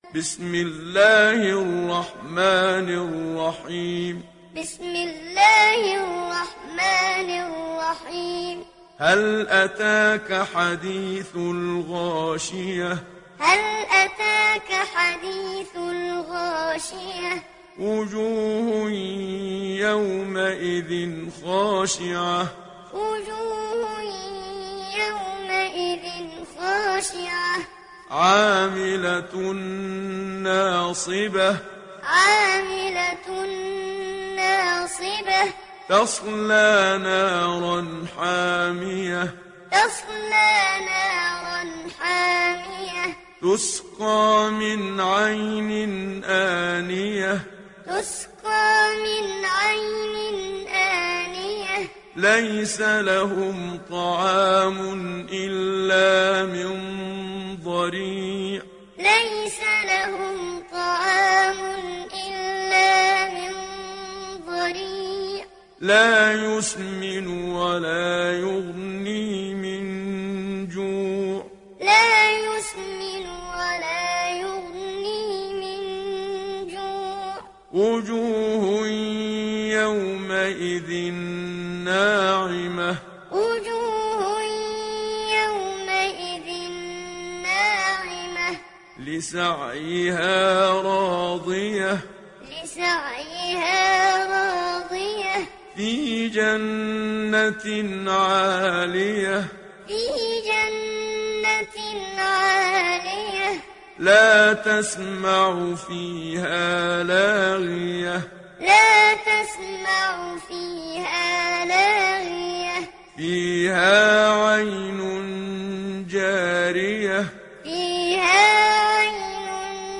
تحميل سورة الغاشية mp3 بصوت محمد صديق المنشاوي معلم برواية حفص عن عاصم, تحميل استماع القرآن الكريم على الجوال mp3 كاملا بروابط مباشرة وسريعة
تحميل سورة الغاشية محمد صديق المنشاوي معلم